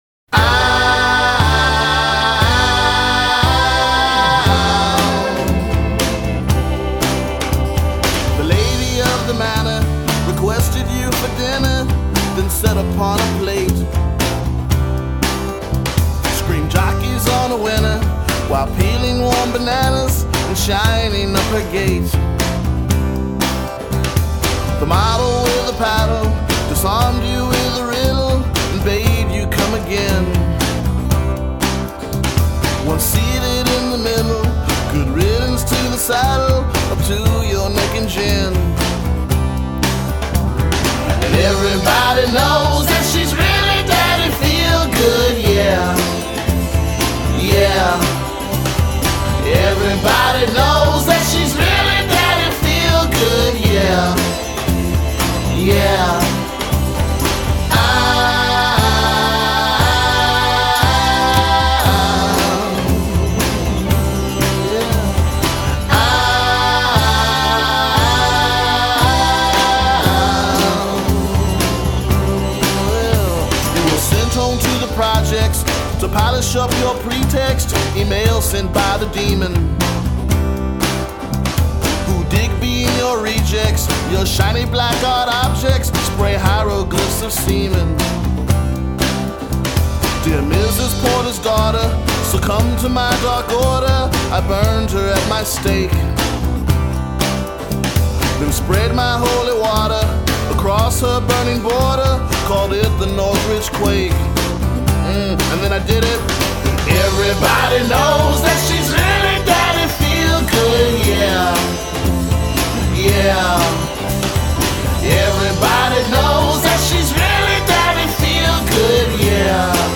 ’ which dazzles with its’ Seussian rhymes and frenetic pace.